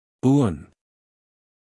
File:Phoneme (Umshk) (Uun) (Male).mp3
Audio of the "Uun" phoneme in Umshk (pronounced by male).